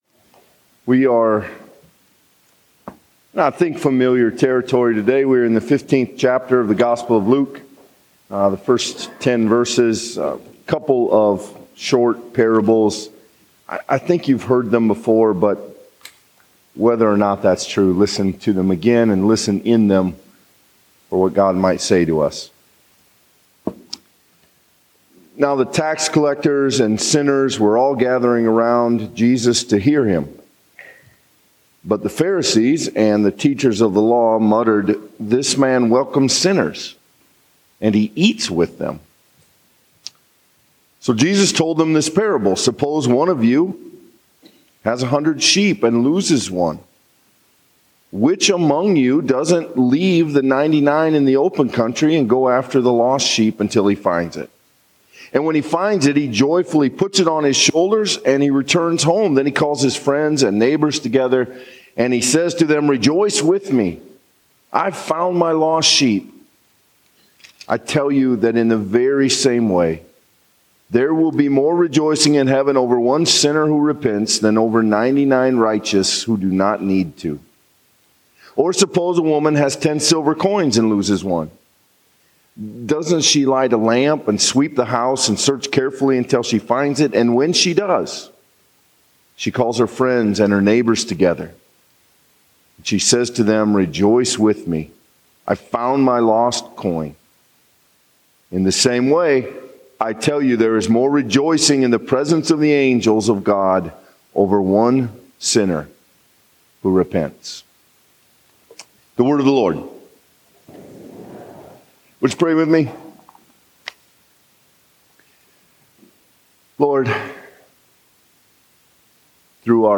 Sermons & Bulletins